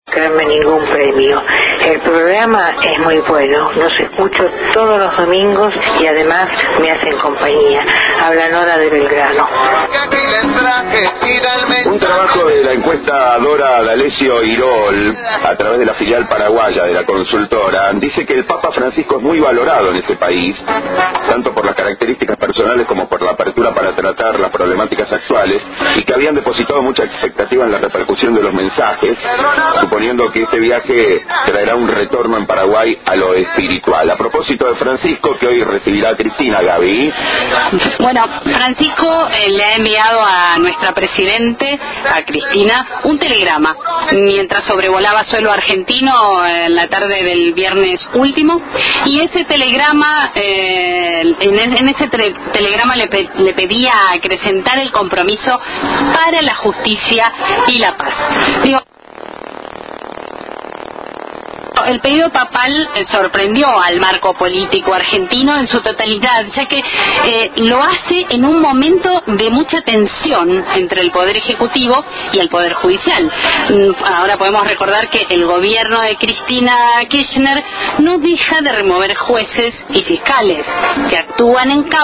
La filial paraguaya de la consultora D’Alessio IROL realizó una encuesta online ante la llegada del Papa. Para conocer el sentir de los paraguayos escuchá el informe presentado en La Rotonda: